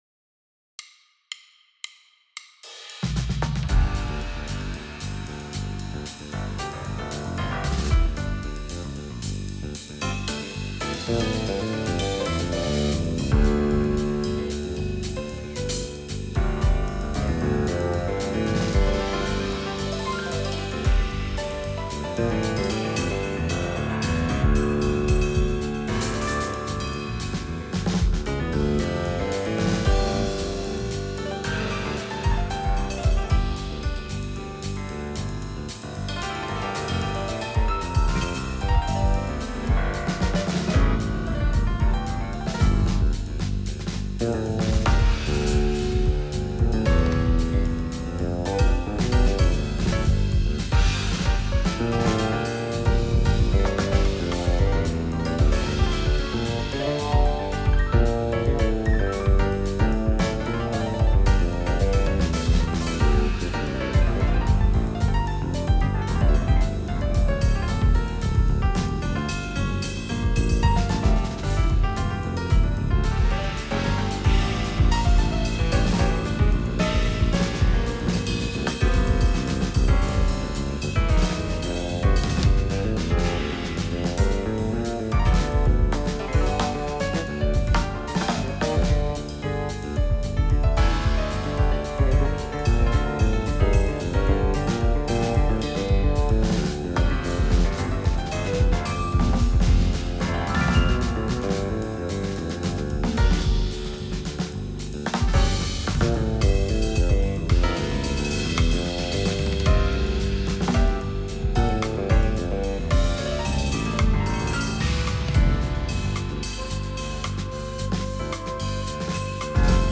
Piano, Basse Fretless, Batterie, Saxophone Soprano